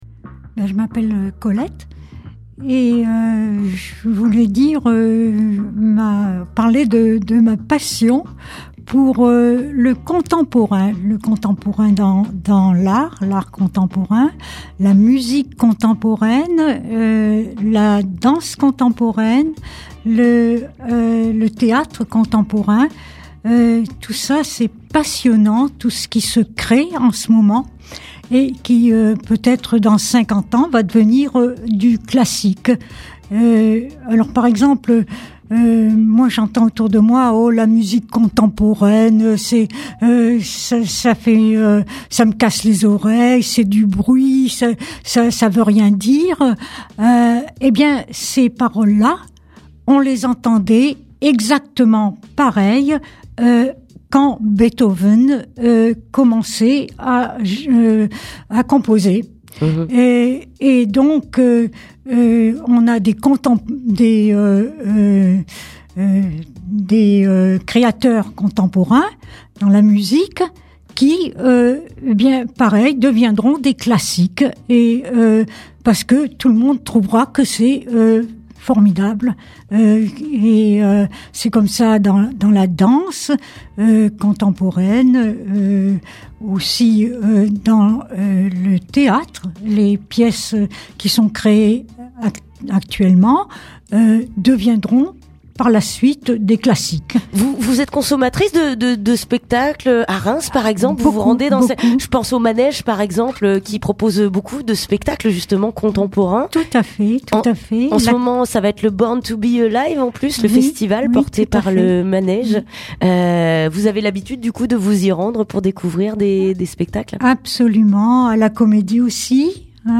Durant l'émission, elle a tenu à prendre la parole pour affirmer son amour pour les arts contemporains!